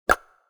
pause-back-click.wav